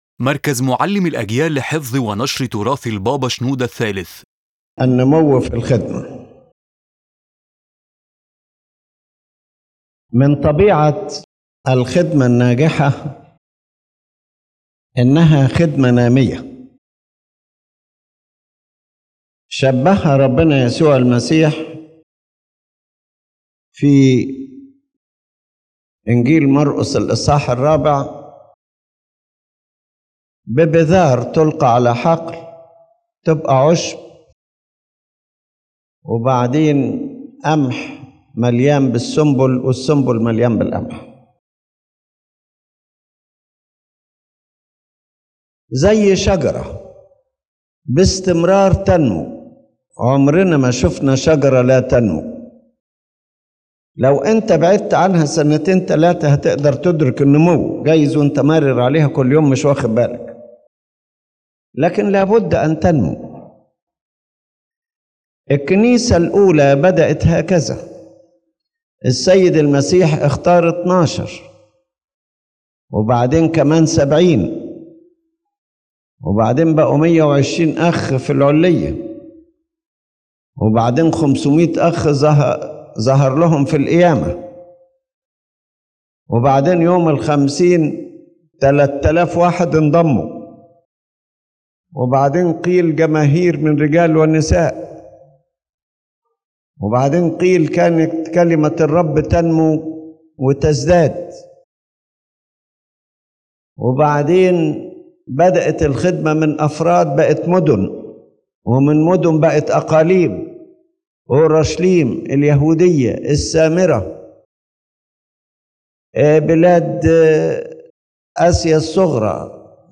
The lecture discusses that successful service is a growing service, and that the growth of service appears in its effect, depth, and spiritual fruits.